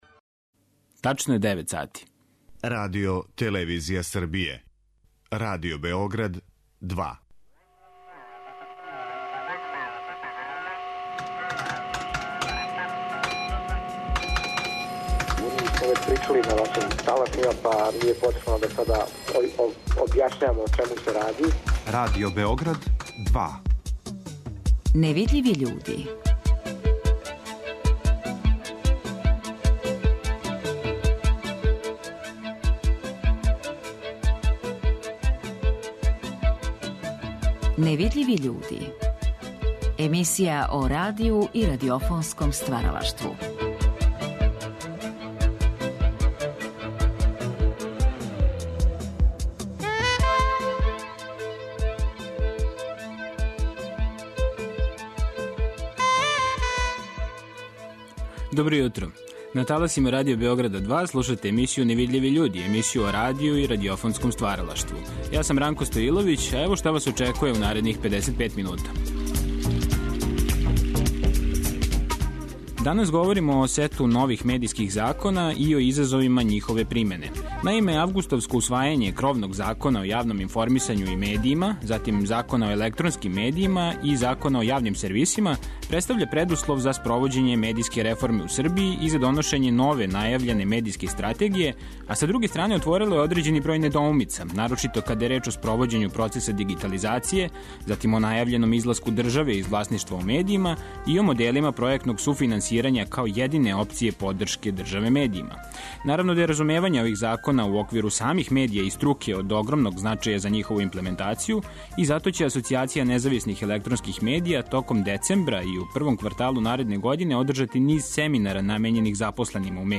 Звучни записи из Тонског архива Радио Београда подсетиће нас како се некада обележавао 29. новембар, Дан Републике у СФРЈ.